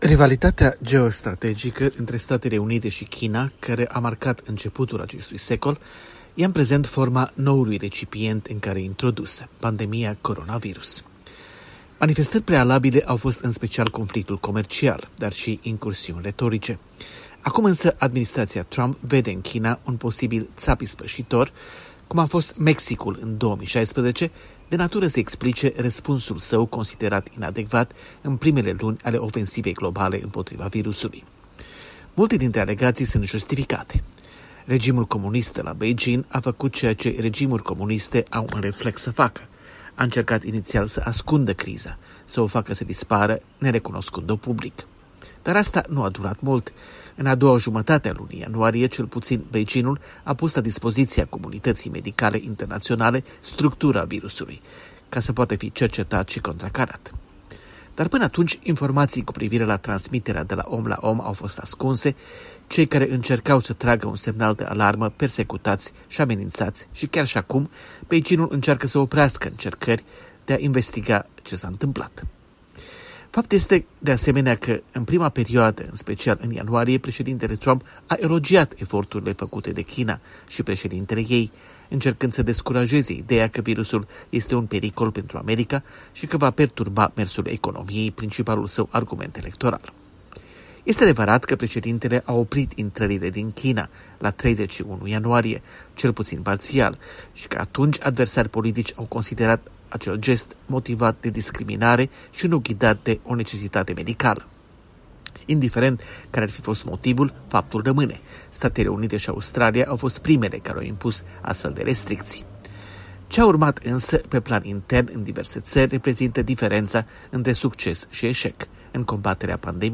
Corespondență de la Washington: China, SUA si Covid-19